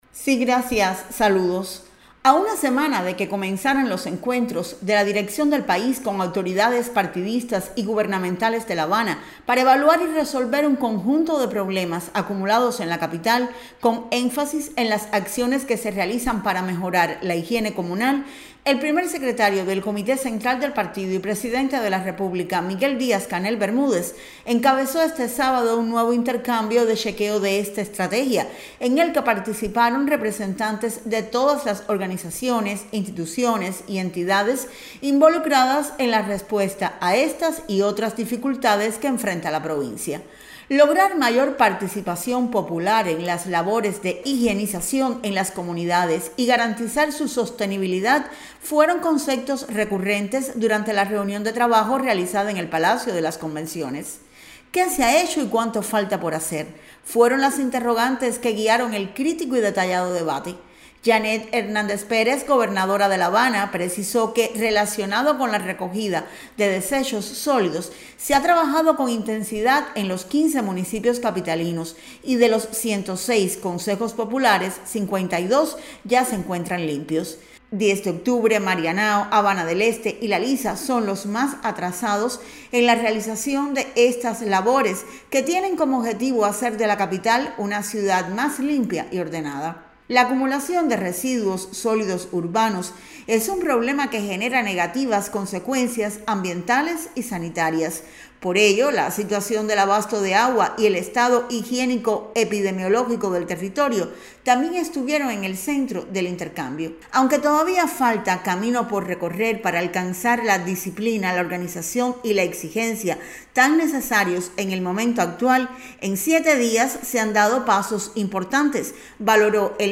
Encabezada por el Primer Secretario del Comité Central del Partido Comunista de Cuba y Presidente de la República, Miguel Díaz-Canel Bermúdez, este sábado en la mañana tuvo lugar, desde el capitalino Palacio de las Convenciones, una reunión de trabajo para analizar resultados de las acciones emprendidas en La Habana desde hace una semana.